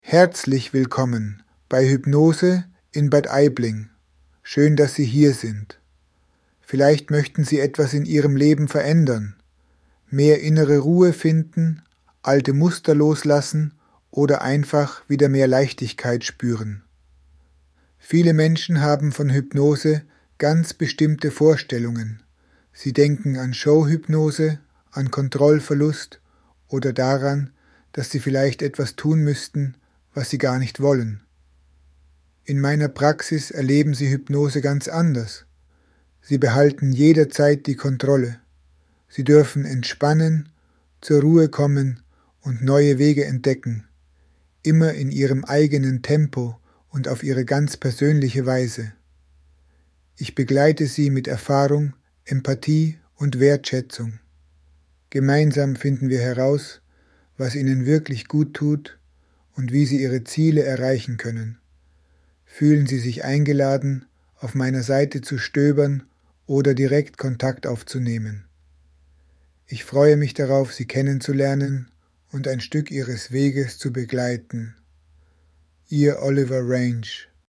Willkommen-Hypnose-Bad-Aibling.mp3